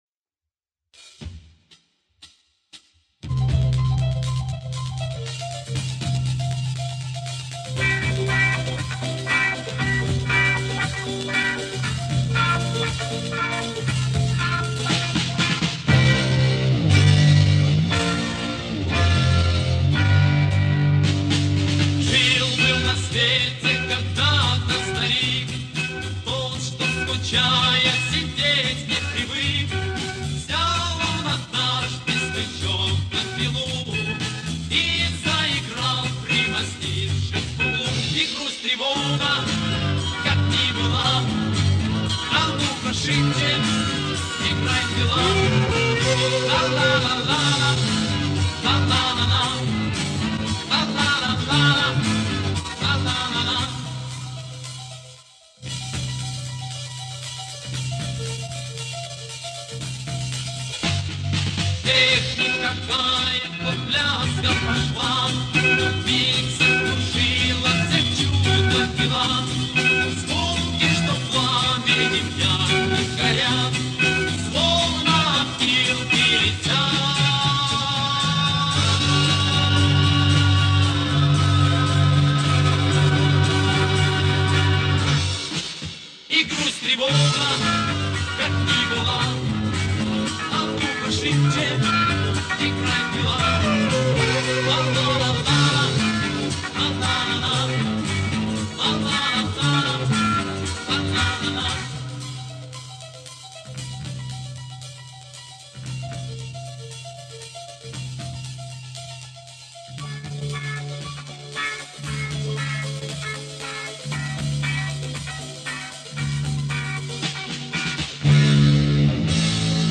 Вот откопал, качество ужасное, кстати и исполнитель неизвестный (может узнаешь?).